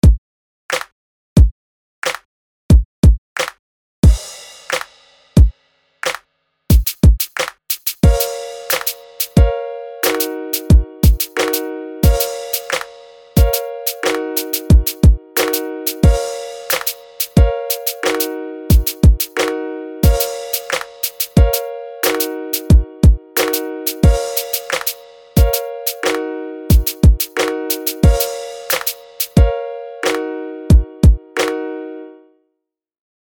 Продаю минуса на тему лирики!
Хип-хоп или Рэп cool